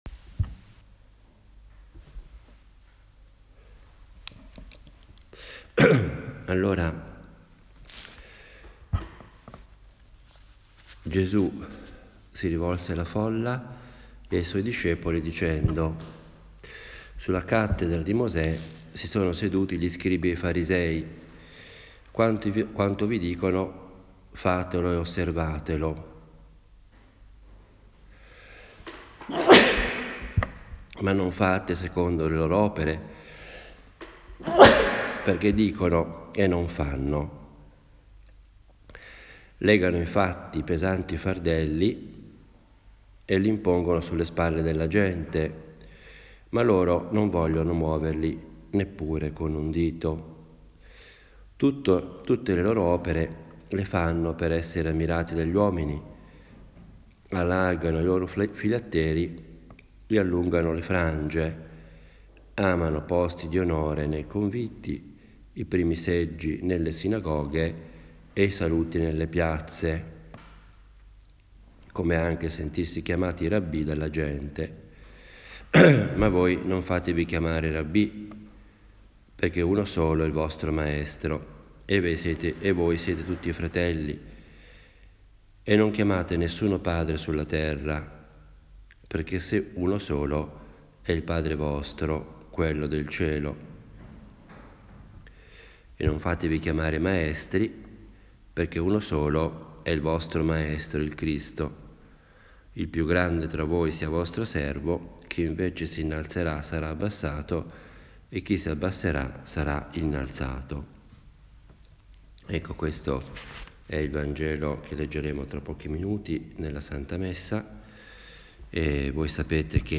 Una “meditazione” è un genere omiletico diverso dalla predica, dal discorso, o dall’allocuzione. Ha il carattere piano, proprio di una conversazione familiare e io la intendo come il mio dialogo personale – fatto ad alta voce – con Dio, la Madonna, ecc.
Le meditazioni che si trovano sul blog sono semplici registrazioni – senza nessuna pretesa particolare – di quelle che faccio abitualmente.